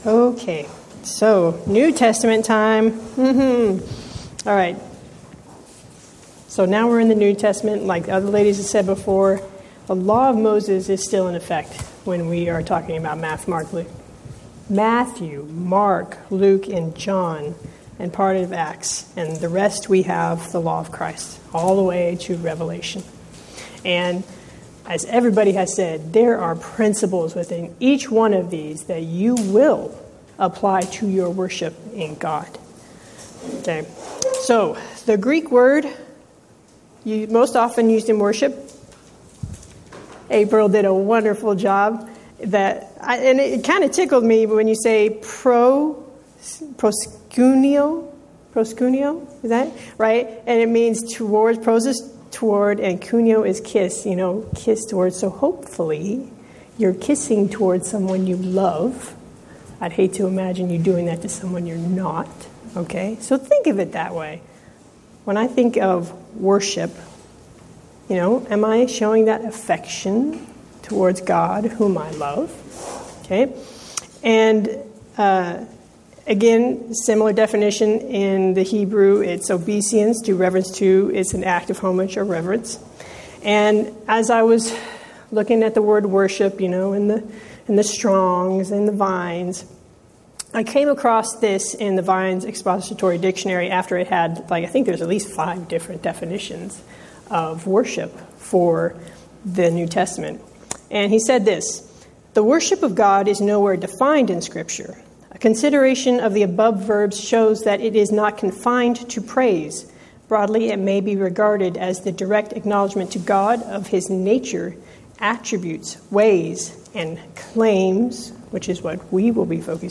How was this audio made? Event: 5th Annual Women of Valor Ladies Retreat